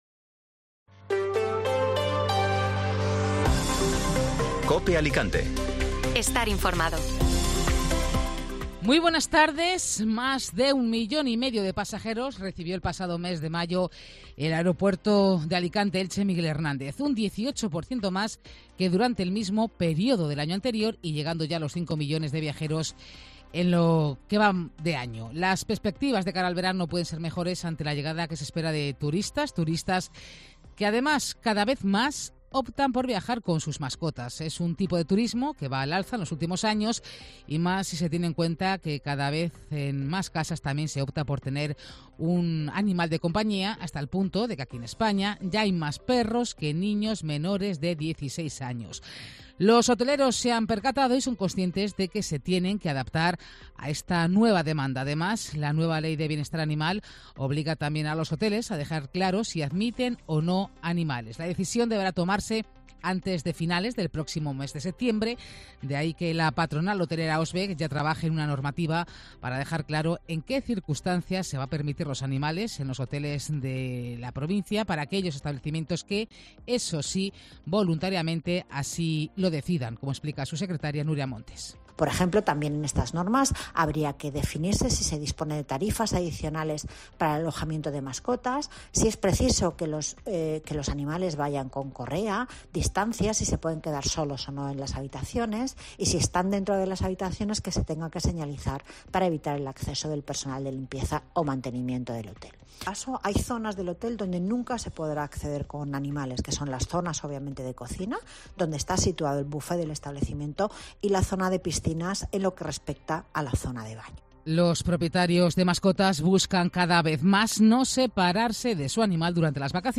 Informativo Mediodía Cope Alicante ( Lunes 12 de junio)